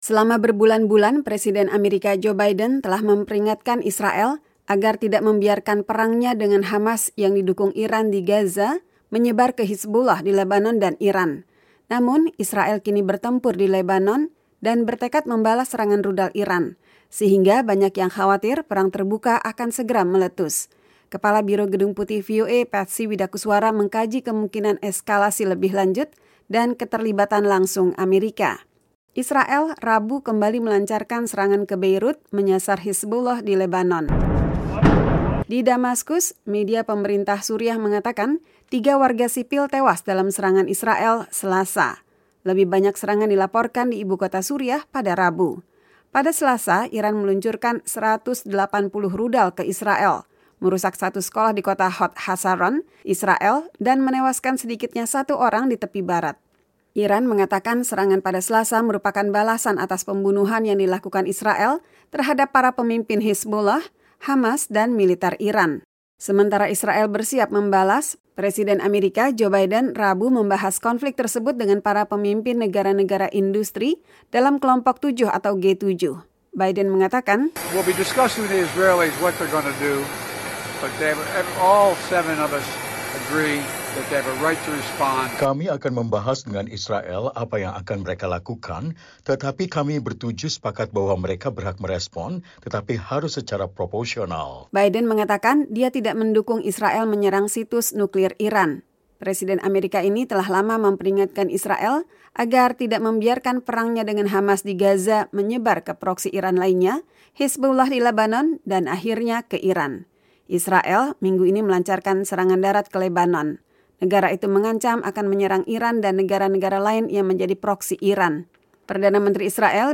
Pengamat Timur Tengah di Carnegie Endowment for International Peace, mantan juru runding Amerika Serikat untuk Timur Tengah, Aaron David Miller, melalui Skype menyatakan, “Itu bisa berarti menyerang fasilitas produksi minyak di Arab Saudi, misalnya.